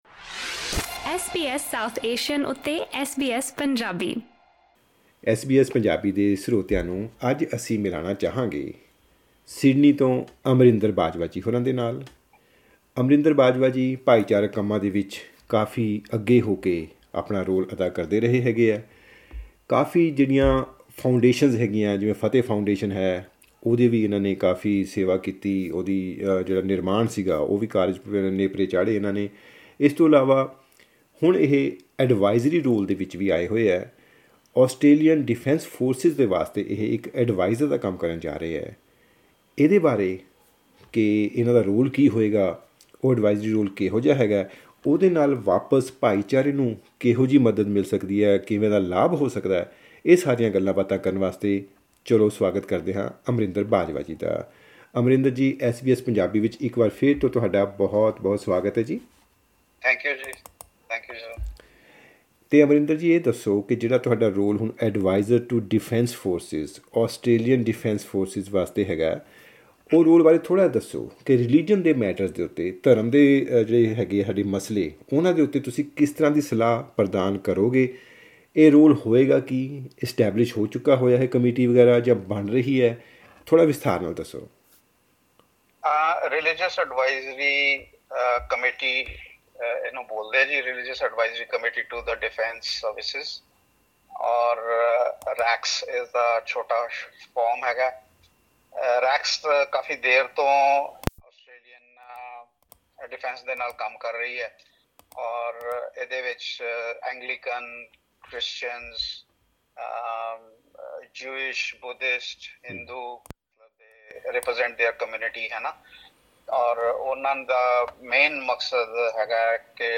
ਇਸ ਗੱਲਬਾਤ ਰਾਹੀਂ ਜਾਣੋ।